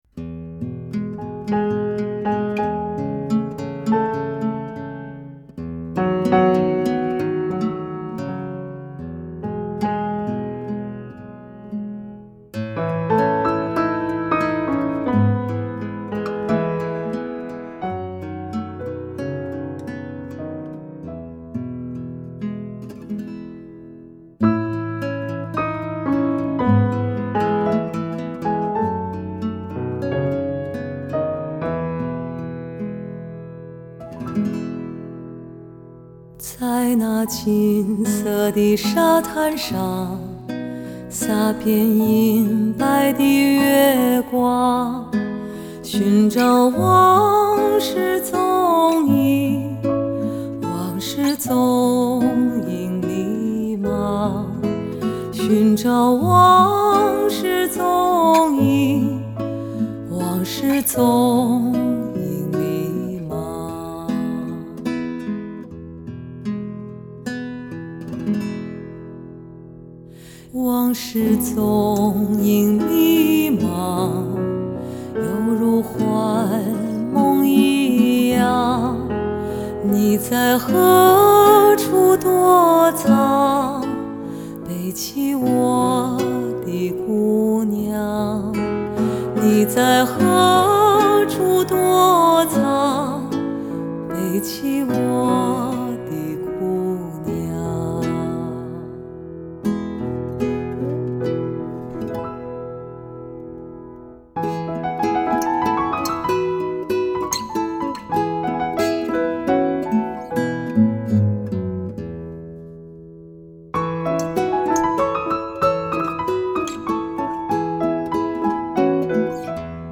从价值百万的斯坦威钢琴到全新感觉的电小提琴
不变的是那深海三千尺的宽厚音色，变化的是曾经那阳光般温暖的男声已转化为金丝绒般柔软的妩媚之调。